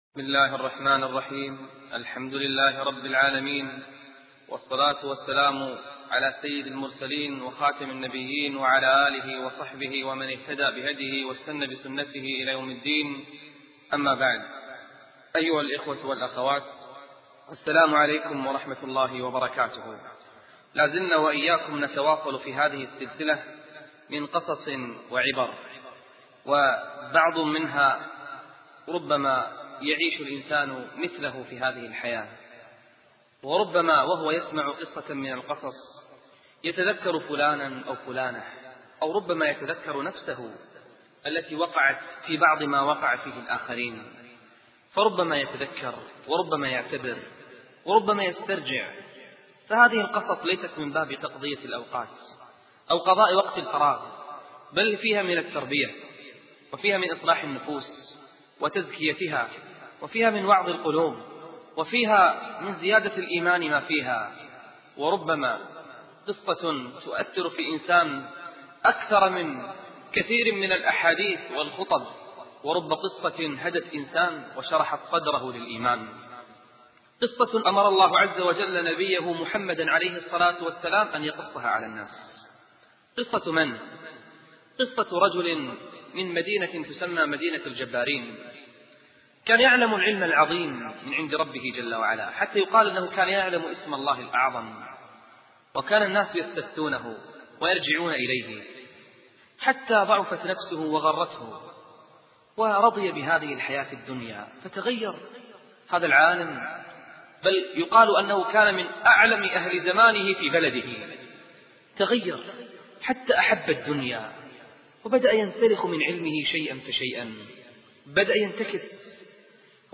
أرشيف الإسلام - ~ أرشيف صوتي لدروس وخطب ومحاضرات الشيخ نبيل العوضي